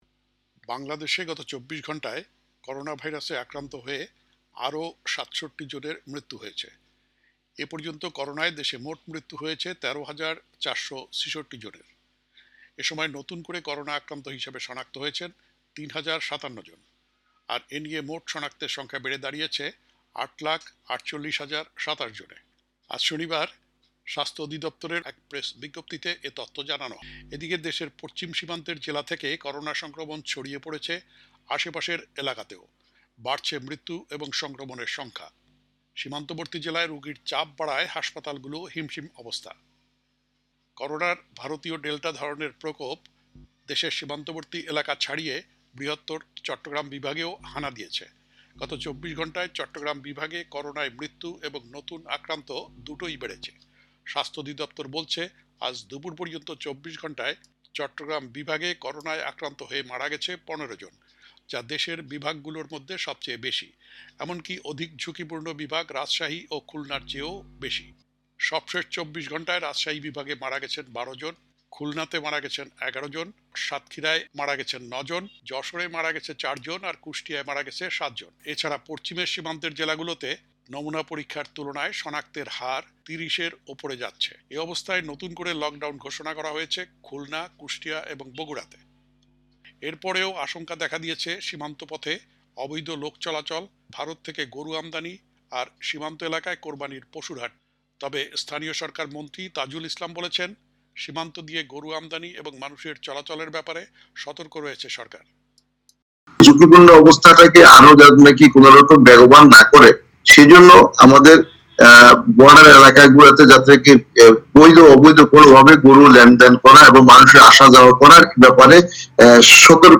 বাংলাদেশে আতঙ্ক বাড়াচ্ছে করোনার ভারতীয় ডেল্টা ভ্যারিয়েন্ট: বিশ্লেষক প্রতিক্রিয়া